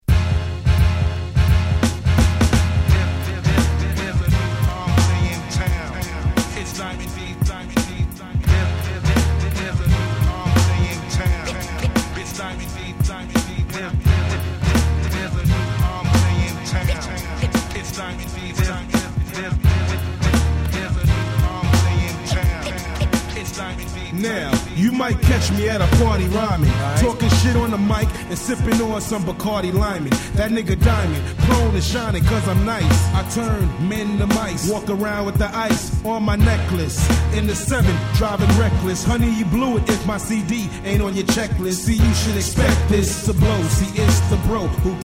ダイアモンド 90's Boom Bap ブーンバップ プロモオンリー